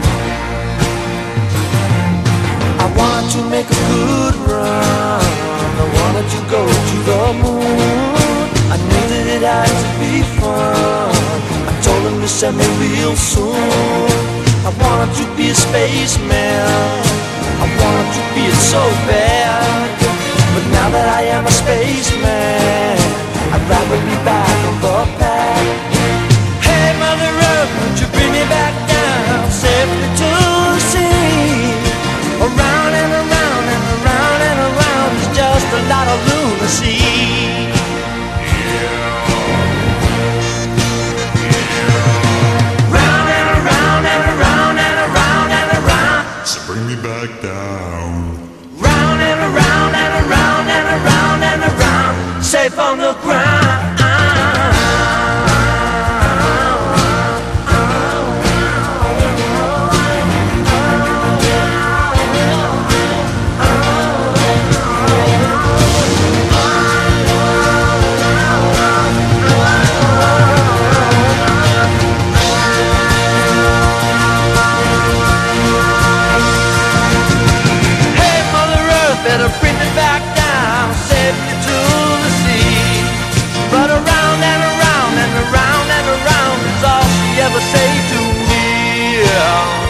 SOUL / SOUL / 70'S～
鳥肌モノのドラム・ブレイクが炸裂するカラフル・ファンキー・ソウル
喘ぎ声やユーモアなフレーズが乱打される間奏がたまりません。